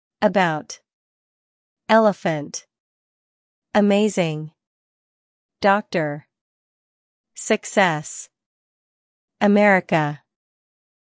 There’s also /ə/, the “schwa” in “comma”—a lazy, neutral English sound common in unstressed syllables.